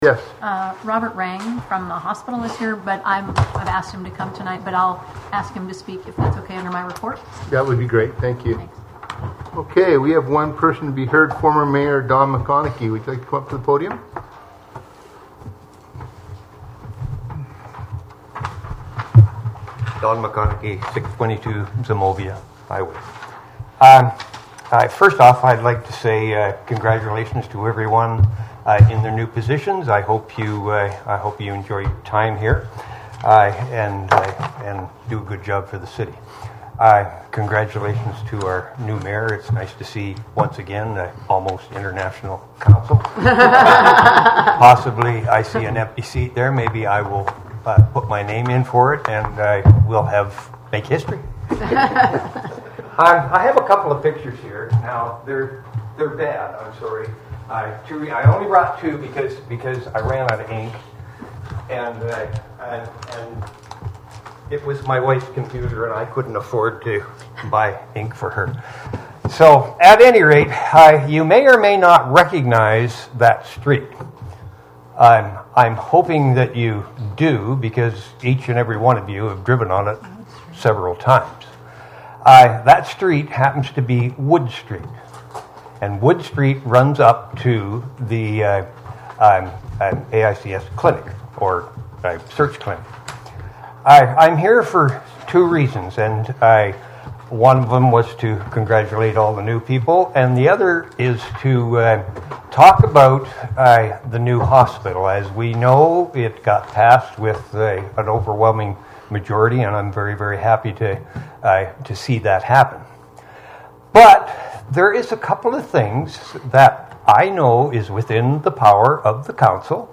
Wrangell Borough Assembly met for a regular meeting on October 11, 2018.